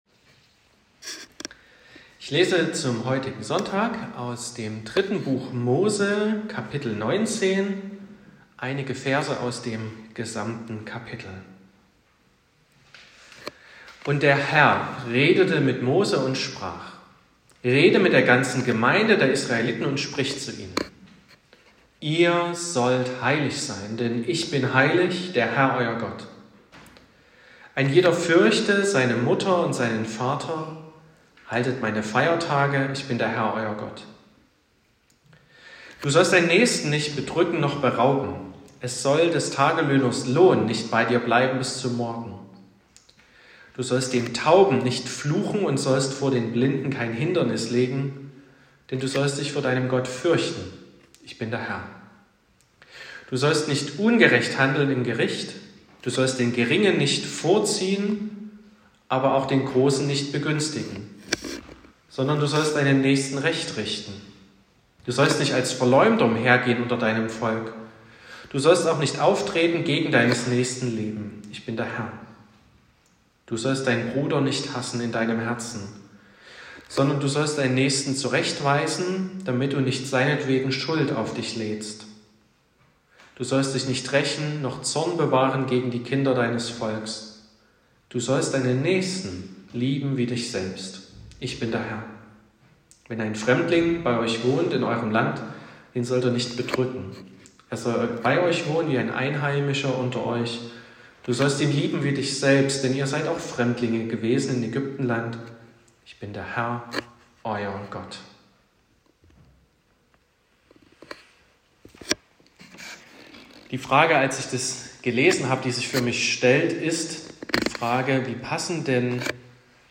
25.08.2024 – Gottesdienst
Predigt (Audio): 2024-08-25_Heiliger_Gott__heiliges_Leben.m4a (8,9 MB)